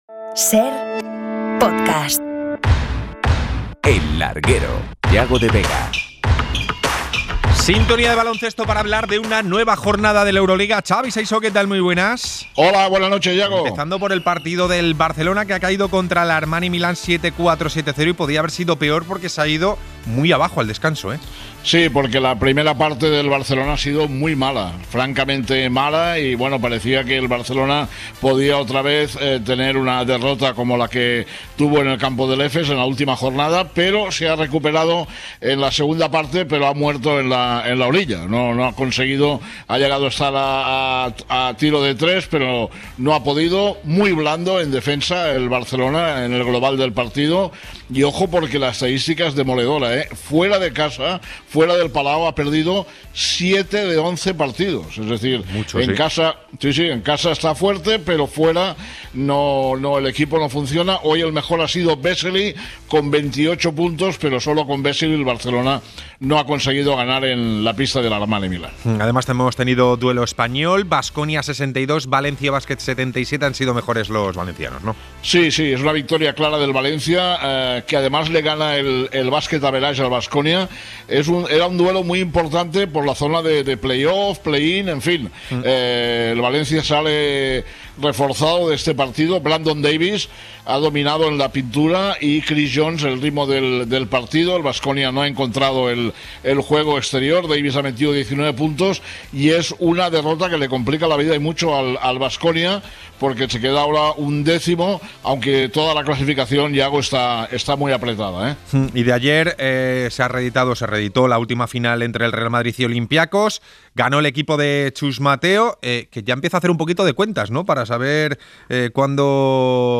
Hablamos con un futbolista hispano palestino en la Copa Asia y entrevista a Adriana Cerezo